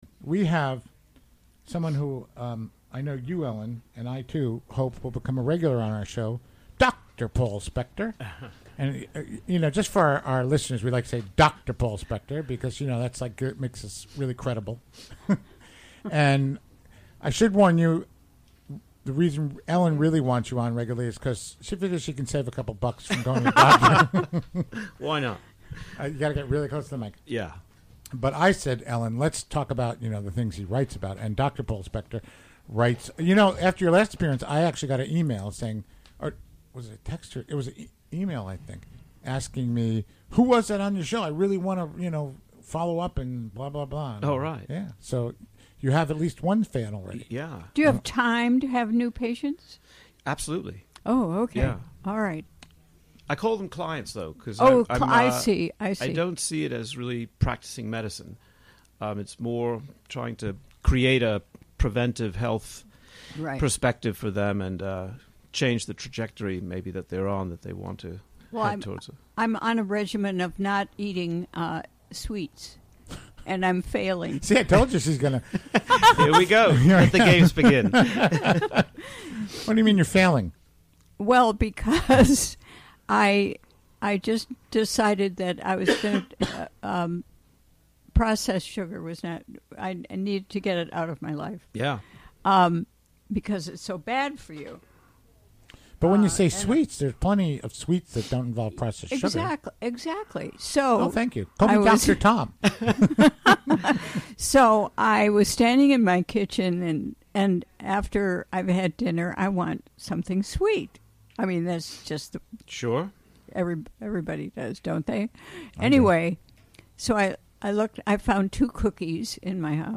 Recorded live during the WGXC Afternoon Show Thursday, March 22, 2018.